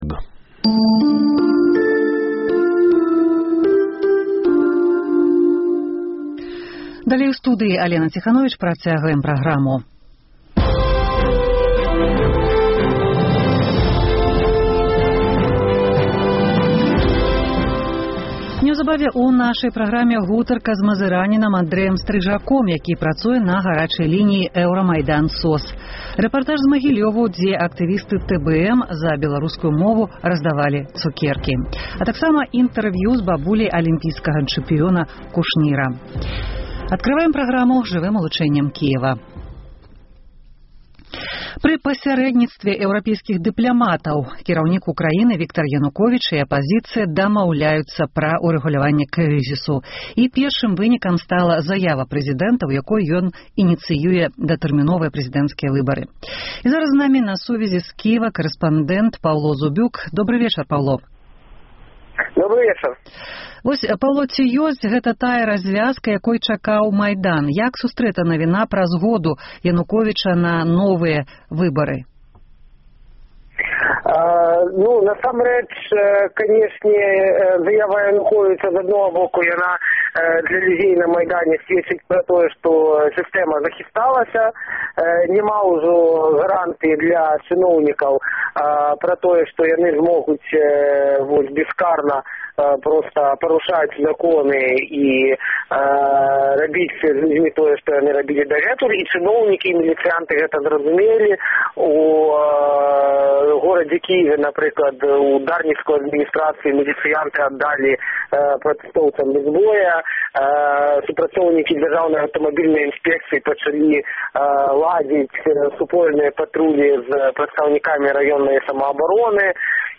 Чаму беларускія аўтаперавозчыкі адмаўляюцца ад рэйсаў у рэвалюцыйную сталіцу? Гутарка